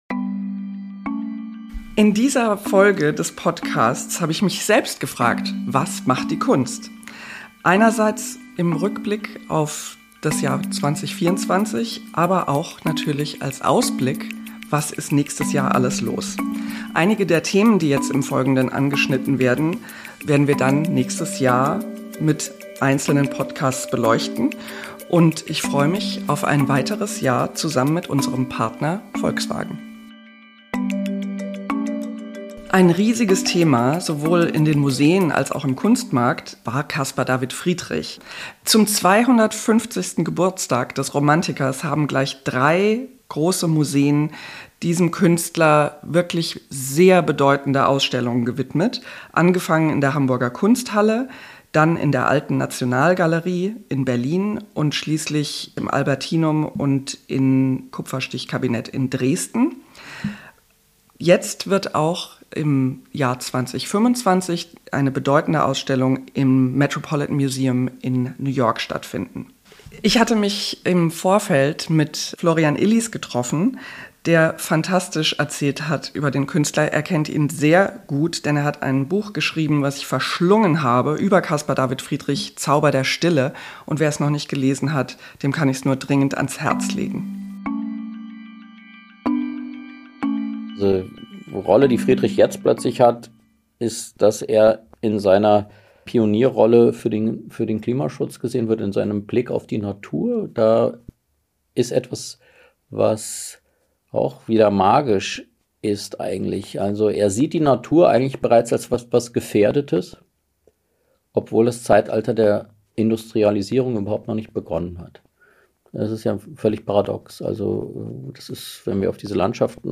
Ein Gespräch über Form und Bedeutung, Erinnerung und Haltung und darüber, warum Kunst mehr ist als Dekoration.